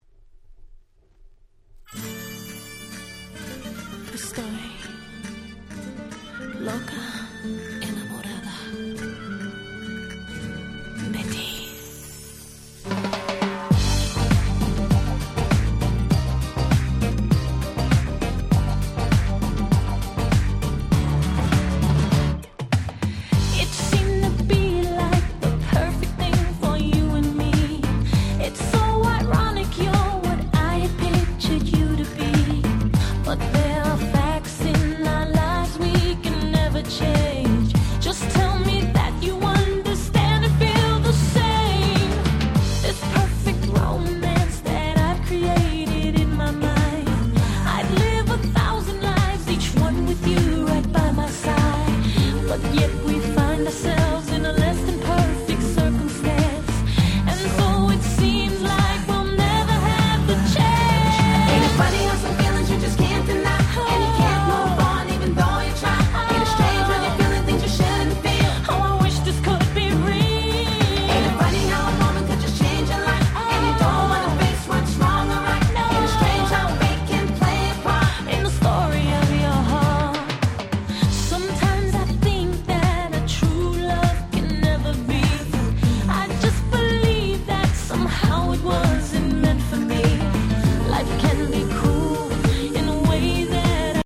01' Super Hit R&B !!